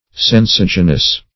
Meaning of sensigenous. sensigenous synonyms, pronunciation, spelling and more from Free Dictionary.
Search Result for " sensigenous" : The Collaborative International Dictionary of English v.0.48: Sensigenous \Sen*sig"e*nous\, a. [L. sensus sense + -genous.] Causing or exciting sensation.